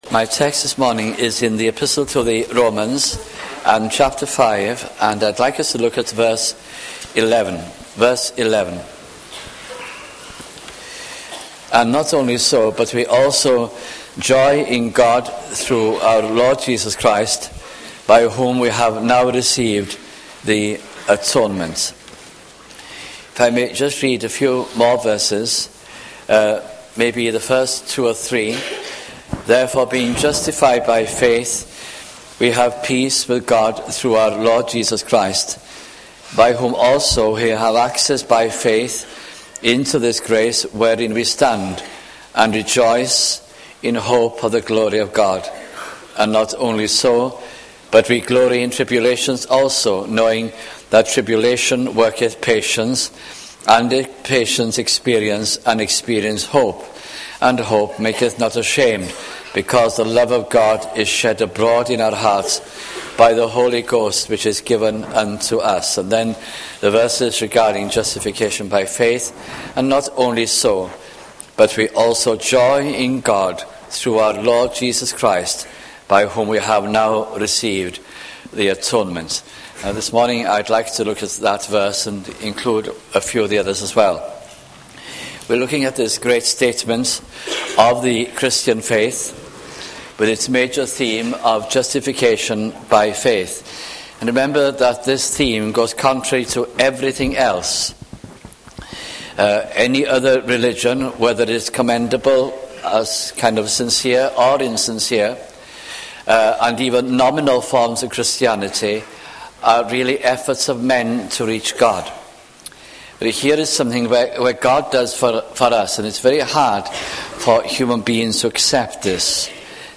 » Romans 1996-98 » sunday morning messages